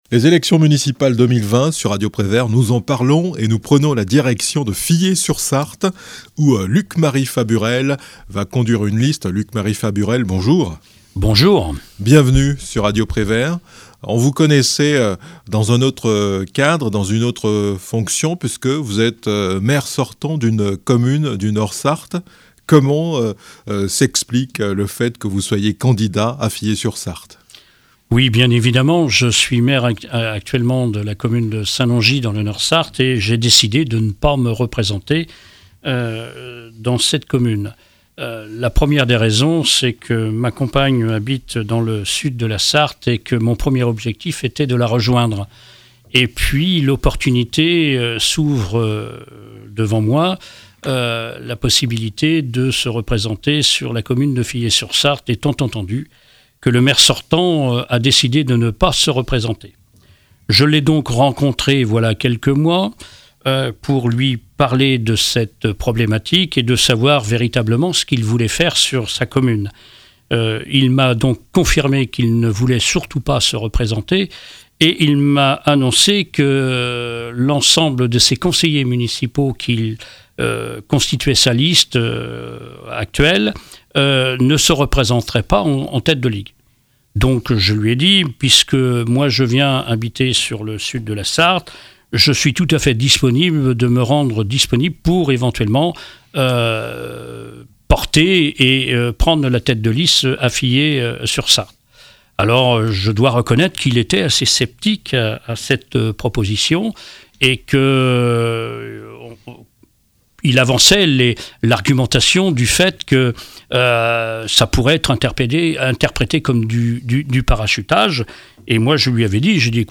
L'élu va désormais prendre la tête de la liste qui sollicite les suffrages des électeurs à Fillé-sur-Sarthe. Invité de Radio Prévert, il précise les conditions entourant sa candidature, présente l'équipe qui l'accompagne, évoque les atouts de la commune et trace les perspectives pour l'avenir.